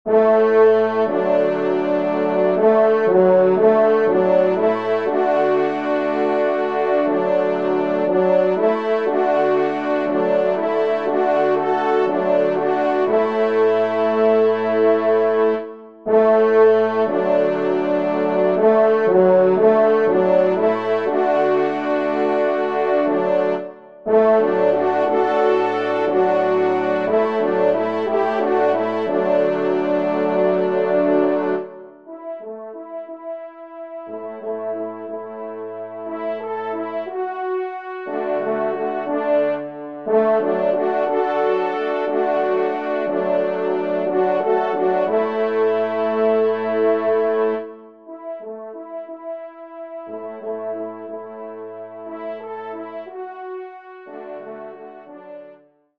Genre :  Divertissement pour Trompes ou Cors en Ré
ENSEMBLE